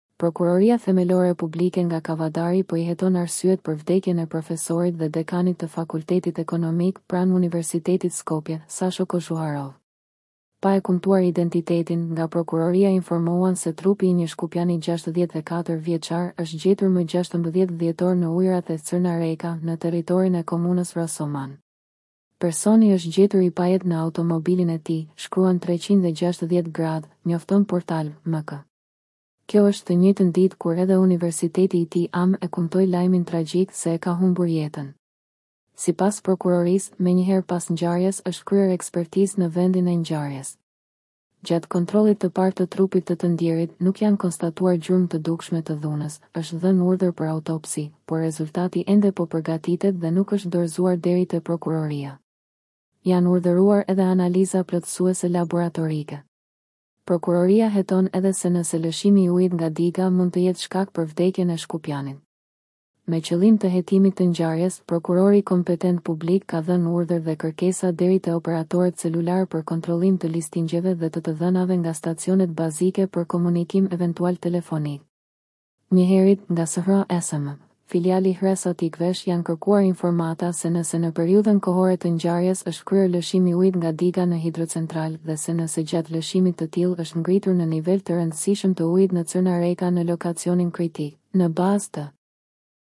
AUDIO LAJM duke përdorur intelegjencën artificiale